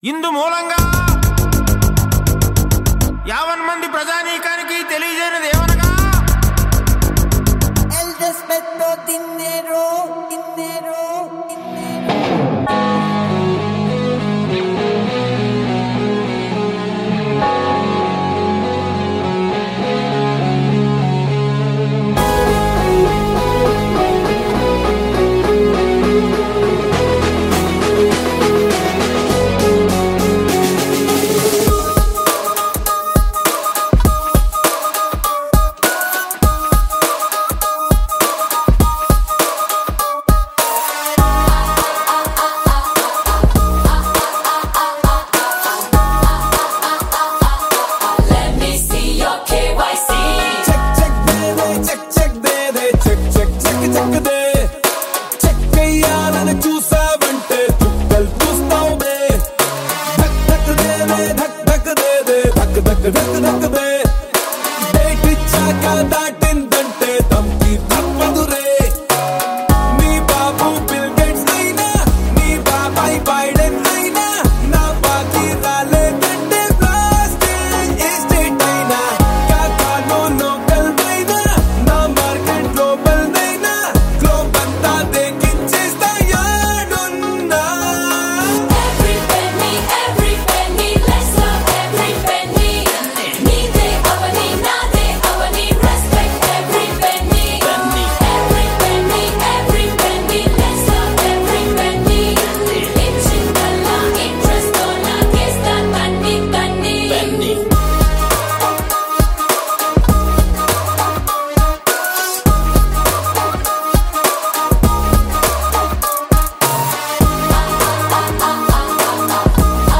Live Percussions